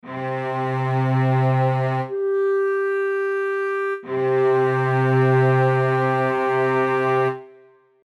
3/1 generates a perfect fifth.
This note is actually an octave plus a fifth above the tonic.